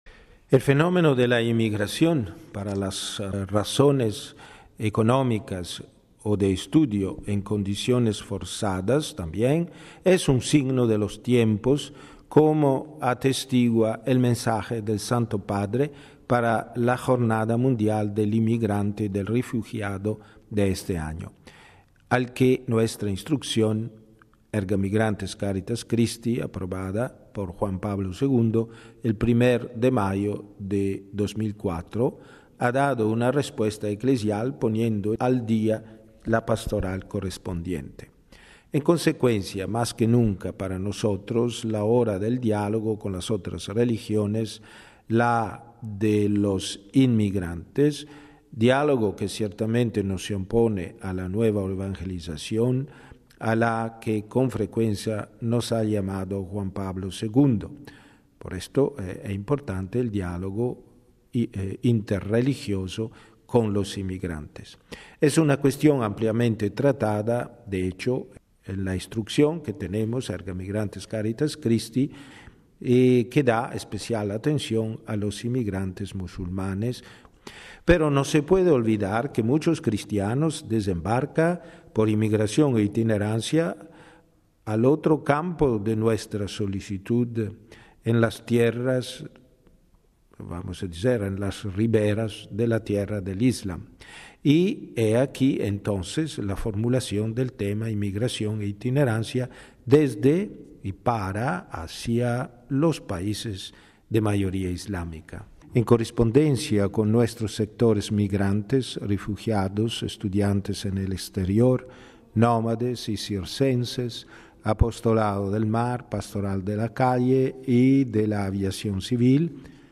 Y, en el marco de esta plenaria, les ofrecemos, precisamente, la declaración de Mons. Marchetto explicándonos por qué está dedicada al fenómeno de la migración y de la movilidad desde y hacia los países de mayoría islámica: RealAudio